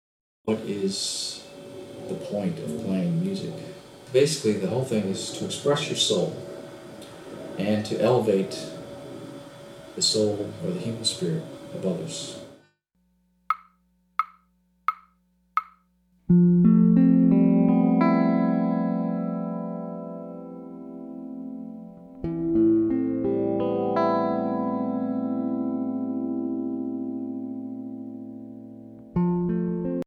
Voicing: Guitar/CD